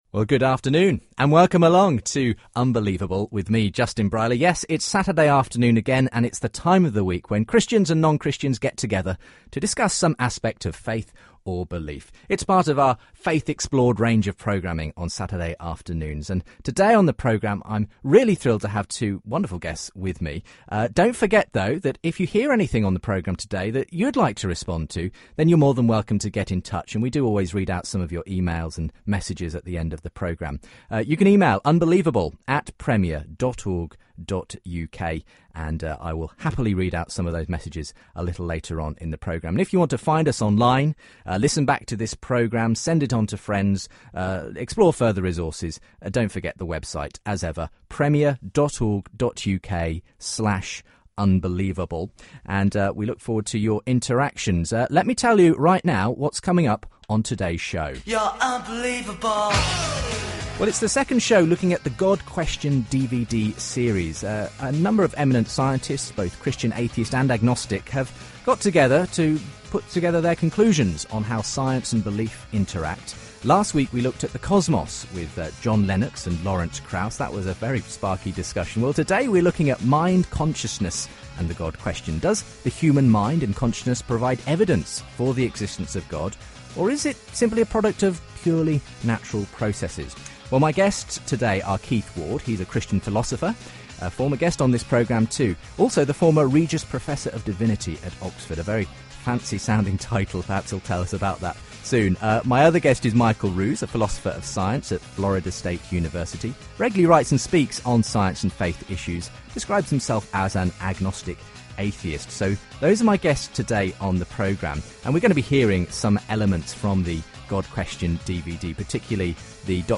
Keith Ward and Michael Ruse debate the evidence for God
His new book “The evidence for God” picks up on various aspects of human experience as proof of a spiritual realm. He is joined by atheist philosopher Michael Ruse for a lively discussion.